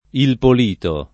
vai all'elenco alfabetico delle voci ingrandisci il carattere 100% rimpicciolisci il carattere stampa invia tramite posta elettronica codividi su Facebook Polito , Il [ il pol & to ] tit. — dialogo di C. Tolomei (1525) — cfr.